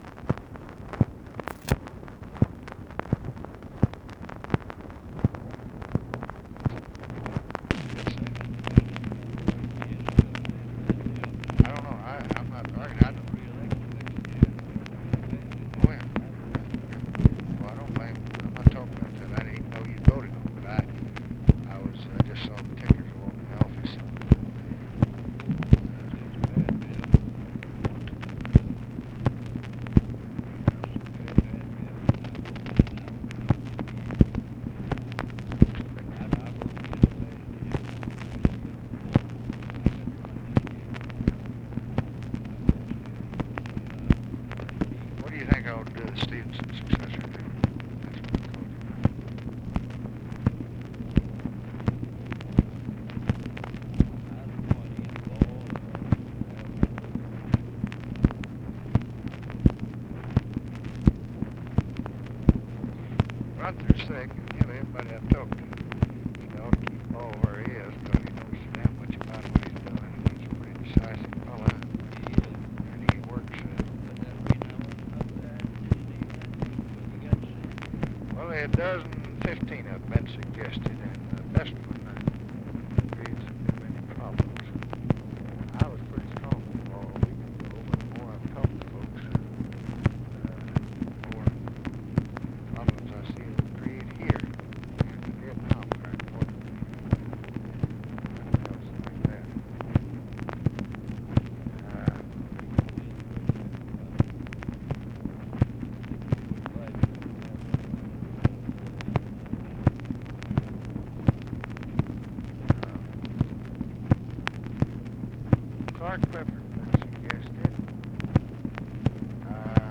Conversation with RICHARD RUSSELL, July 19, 1965
Secret White House Tapes